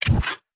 Tab Select 13.wav